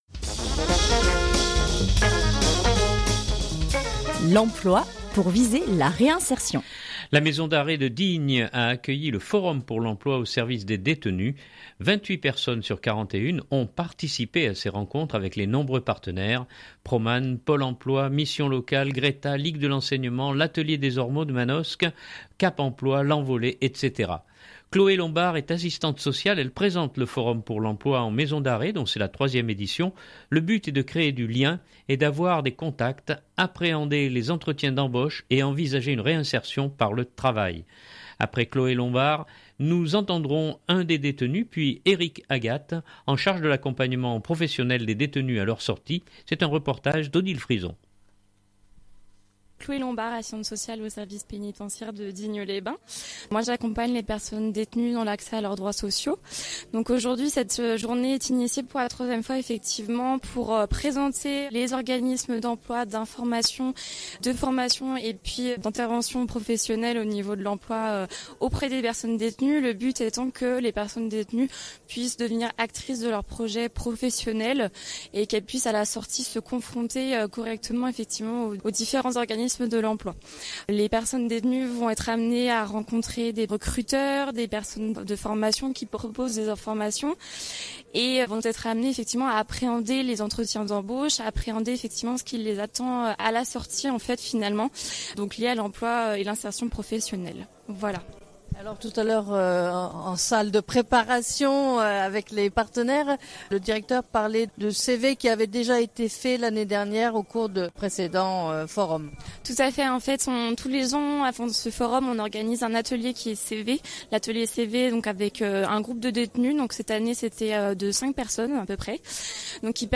nous entendrons un des détenus